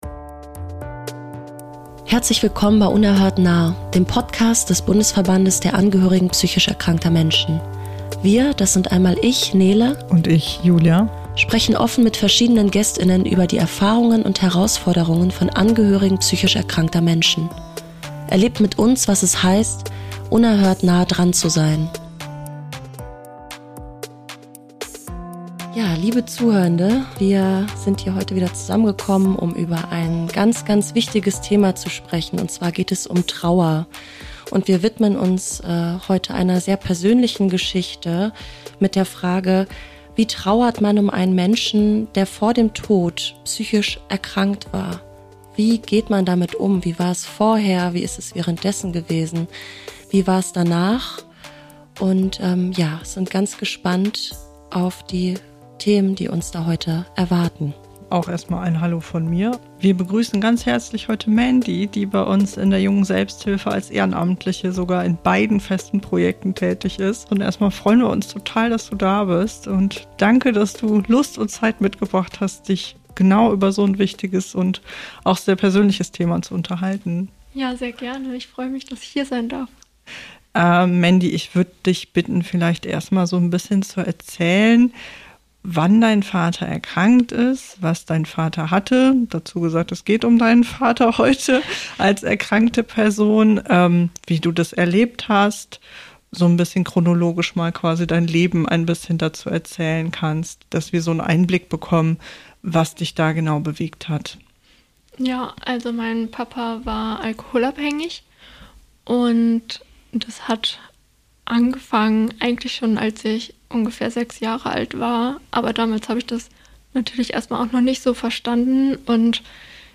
Sie spricht mit uns darüber, wie ungeheuer schwierig es ist, als Angehörige einer psychisch erkrankten Person nach deren Tod zu trauern.